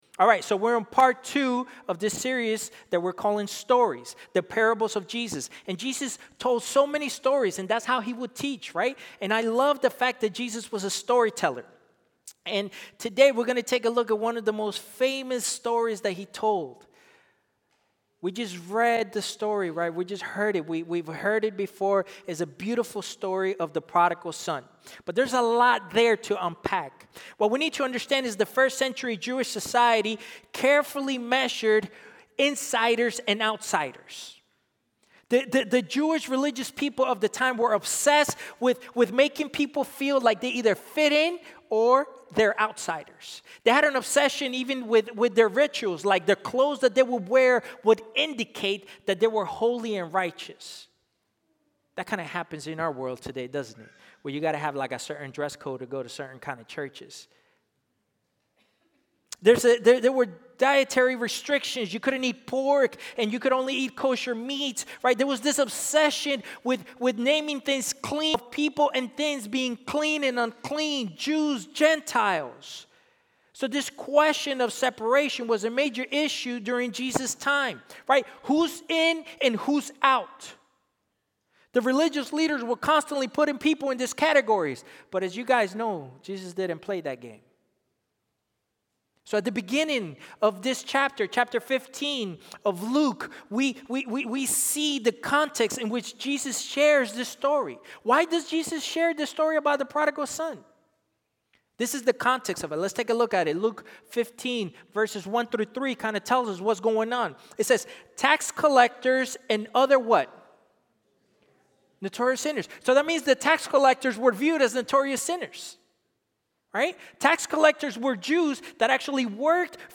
Sermon-Stories-pt.2.mp3